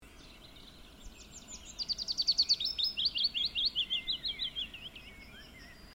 весничка, Phylloscopus trochilus
Administratīvā teritorijaOlaines novads
СтатусПоёт